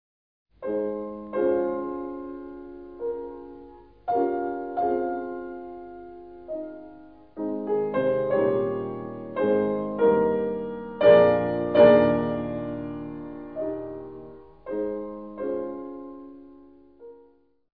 Franz Schubert, Moment musical Nr. 6, As-Dur, D 780 Nr. 6
Das Hauptthema des letzten der "Moments musicaux" von Franz Schubert basiert ganz auf Vorhalten: Zunächst ein Septvorhalt zur Sixte ajoutée des zweiten Taktes, dann ein oktavierter Sextvorhalt, schließlich ein Doppelvorhalt aus Quart und Septim, der einem Dominantseptimakkord über der Tonika gleichkommt (die Töne der rechten Hand entsprechen einem D7 ohne Quint in der Doppeldominante B-Dur, die der linken der Dominante Es-Dur ohne Terz).